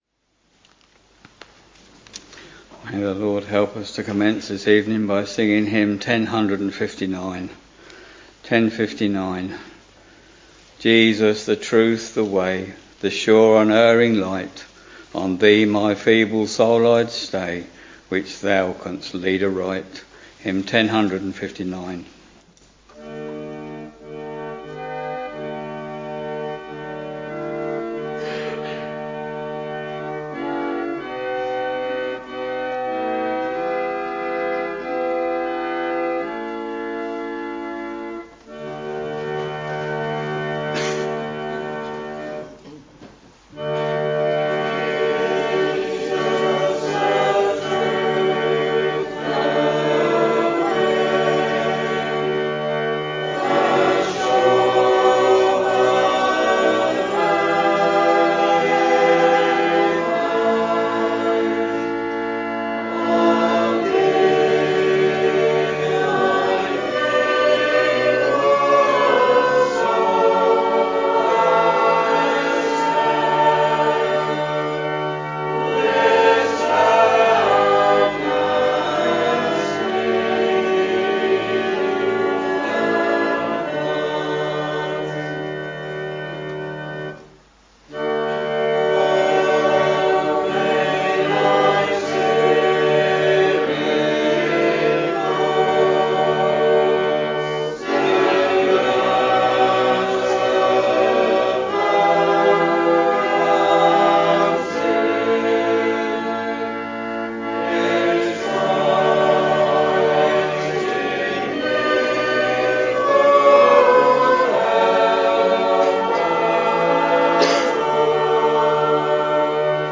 Evening Service Preacher